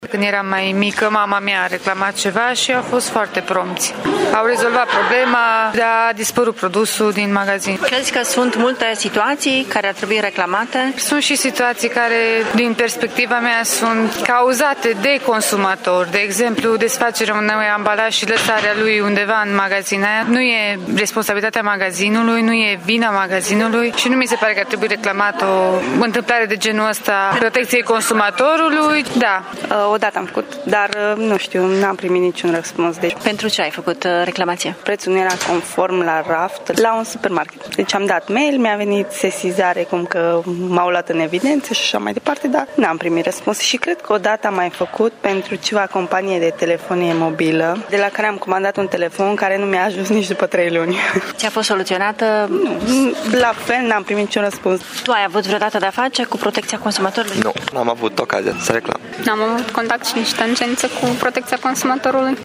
Unii studenți spun că au avut tangență cu protecția consumatorului, iar părerile lor cu privire la eficiența instituției sunt împărțite: